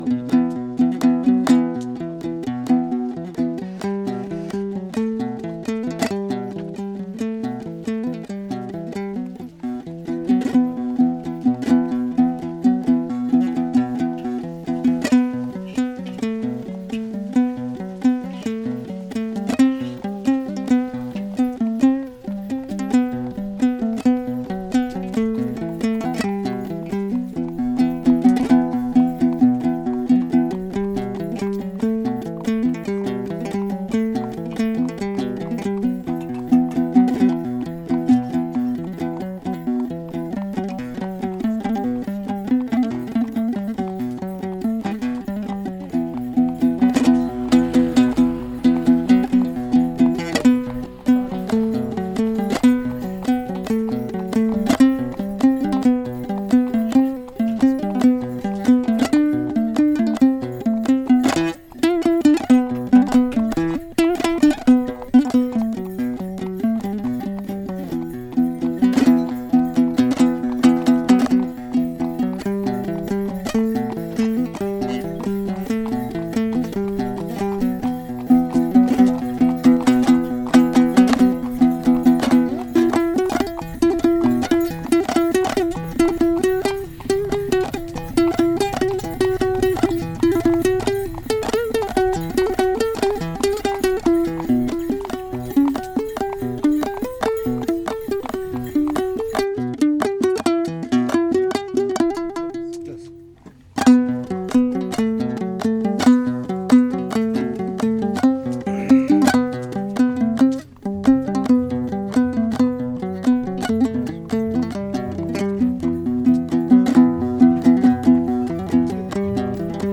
The radio mix contains unedited acoustic recordings made with a pair of RODE NT5 microphones with a NAGRA ARES M digital recorder, and computer modified parts, sound collage and composed processed DONGBRA (Kazakh 2-stringed plucked instrument) and DUTAR (Uyghur long neck 2-stringed plucked instrument).